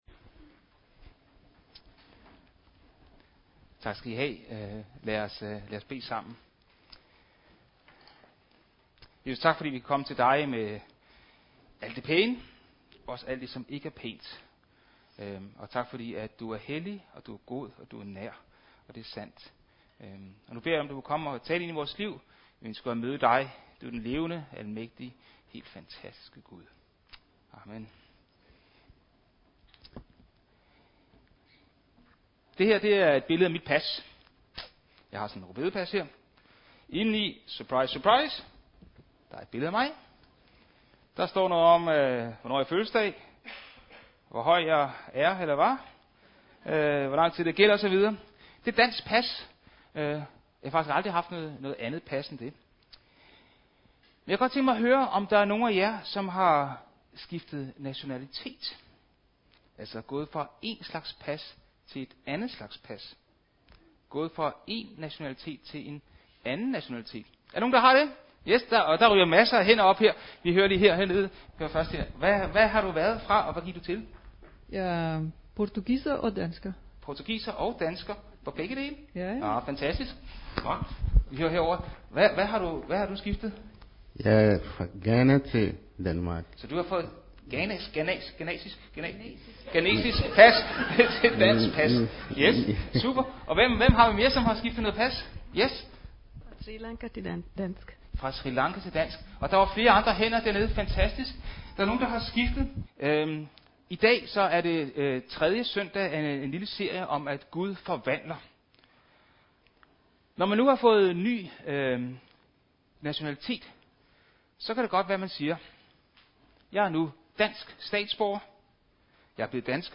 14. maj 2023 Type af tale Prædiken Bibeltekst Johannes Evangeliet MP3 Hent til egen PC